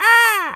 crow_raven_squawk_02.wav